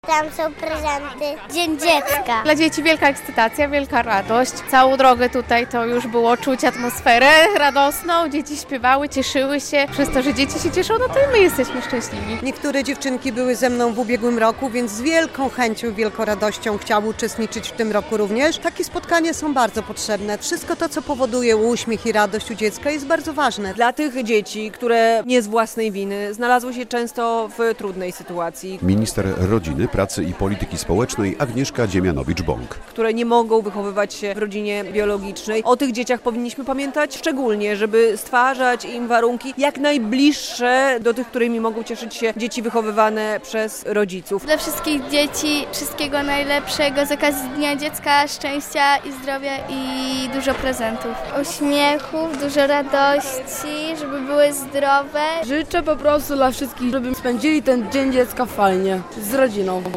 Obchody Dnia Dziecka w Podlaskim Urzędzie Wojewódzkim - relacja